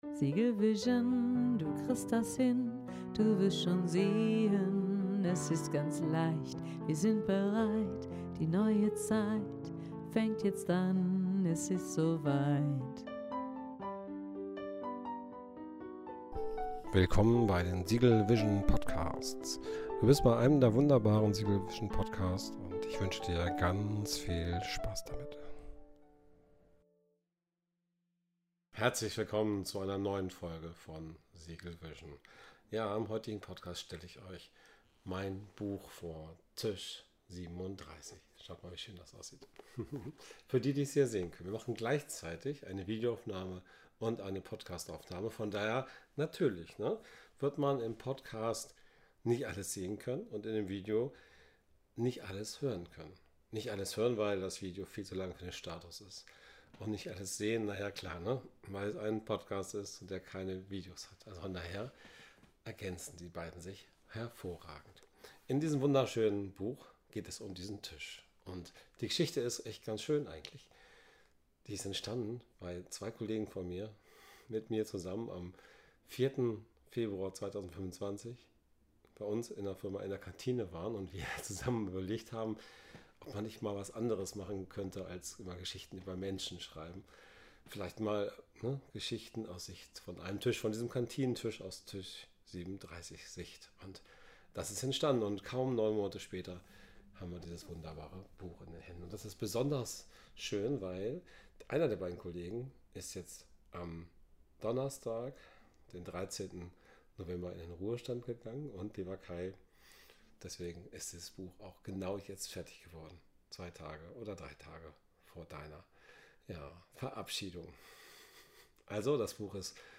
Podcast und Video gleichzeitig aufgenommen Die Entstehungsgeschichte Die Mitwirkenden Vorgelesen: Kapitel 2: Die Putzfrau und die Weisheit des Alltags Hinter dem Tisch 37: Das Wortemodell hinter dem 2A Poetischer Einblick 2B Metho...